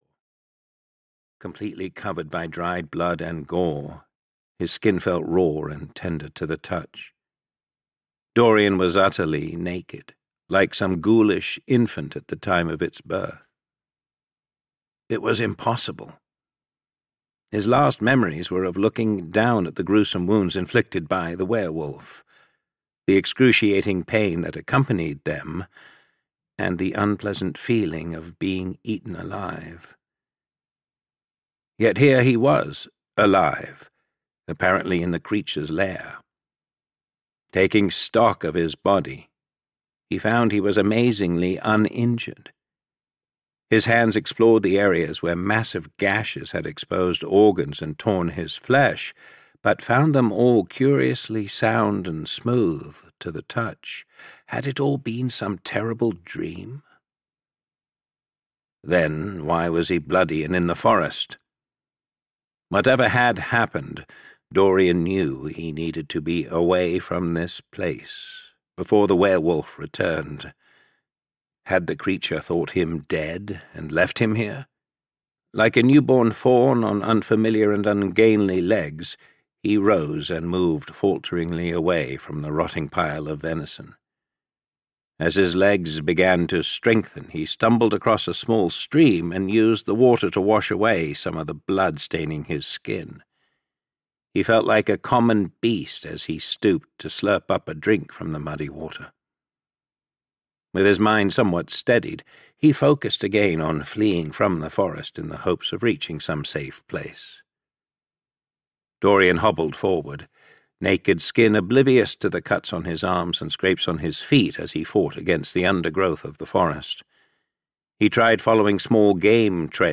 Purgatory of the Werewolf (EN) audiokniha
Ukázka z knihy